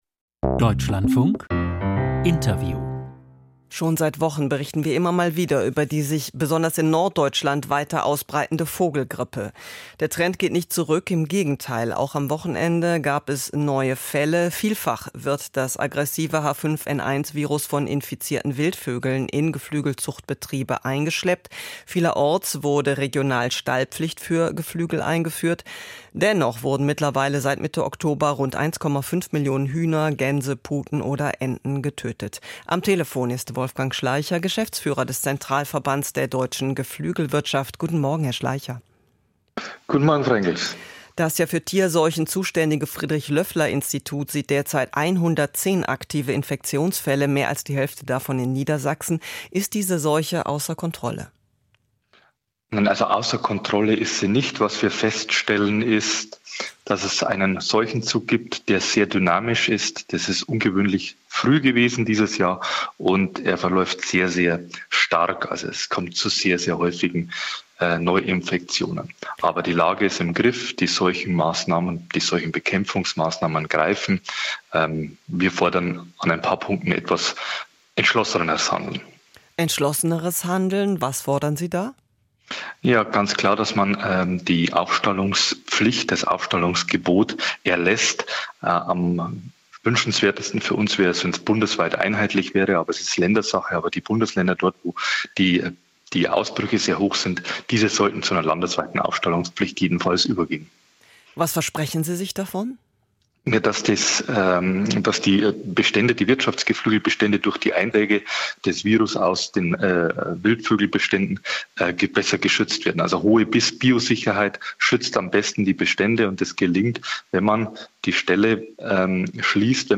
Vogelgrippe grassiert: Interview